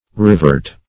riveret - definition of riveret - synonyms, pronunciation, spelling from Free Dictionary Search Result for " riveret" : The Collaborative International Dictionary of English v.0.48: Riveret \Riv"er*et\, n. A rivulet.